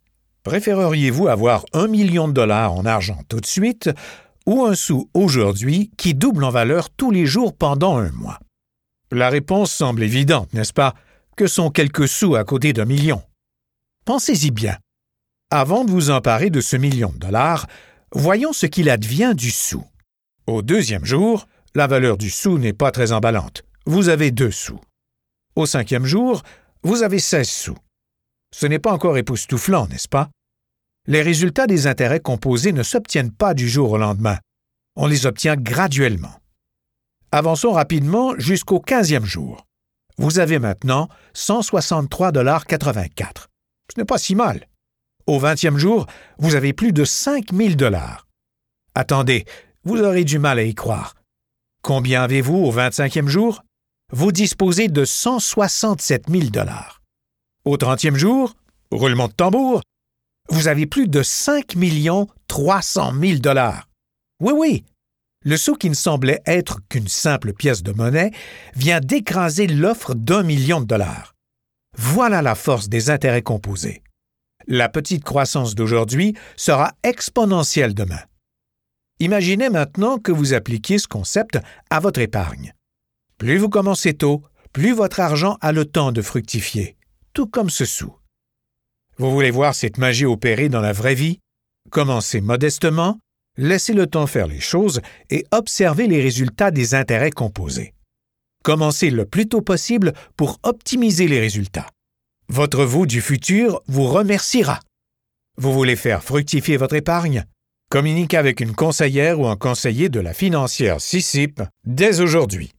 Mature French Canadian VO Artist
Middle Aged
Senior